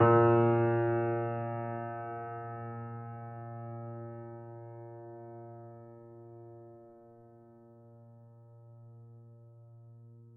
Steinway_Grand